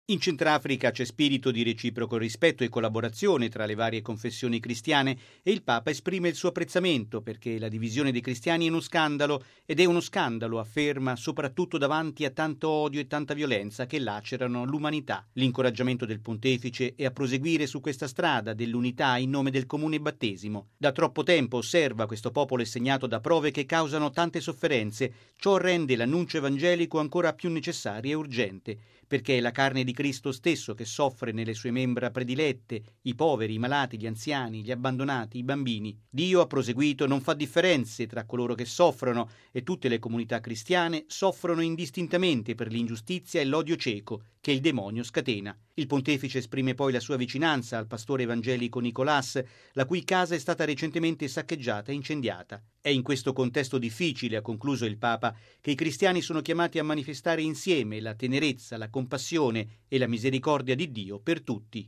Il popolo centrafricano soffre da troppo tempo, per questo annunciare uniti il Vangelo è ancora più urgente: così il Papa durante l’incontro con le comunità evangeliche di Bangui.